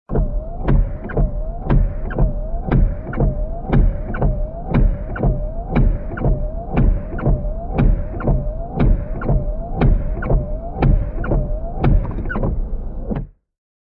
دانلود صدای شیشه پاک کن 2 از ساعد نیوز با لینک مستقیم و کیفیت بالا
جلوه های صوتی
برچسب: دانلود آهنگ های افکت صوتی حمل و نقل دانلود آلبوم صدای شیشه پاک کن ماشین از افکت صوتی حمل و نقل